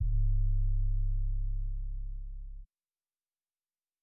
Copy of 808 Hot Wind BLows.wav